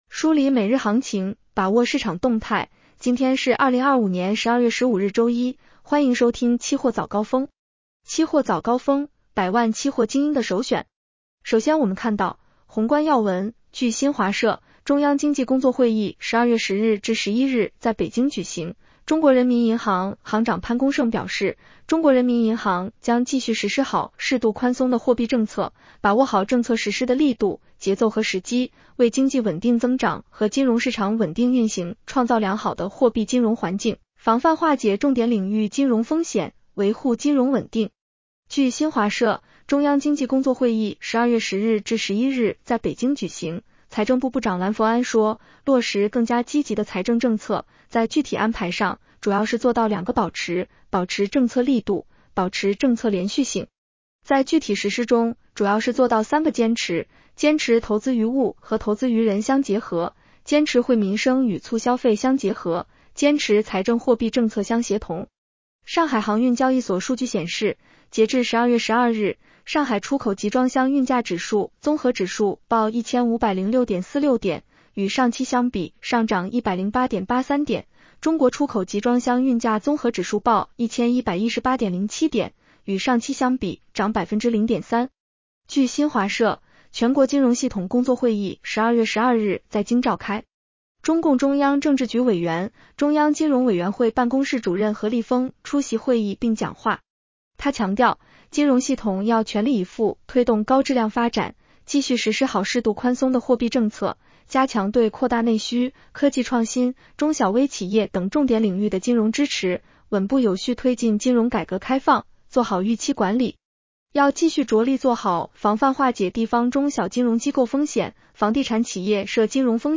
期货早高峰-音频版
期货早高峰-音频版 女声普通话版 下载mp3 热点导读 1.